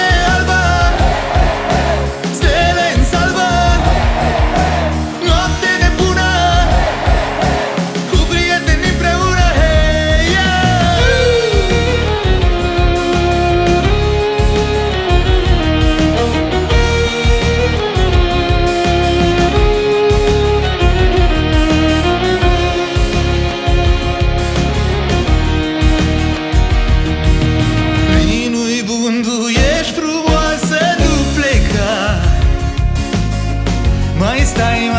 Жанр: Фолк-рок / Рок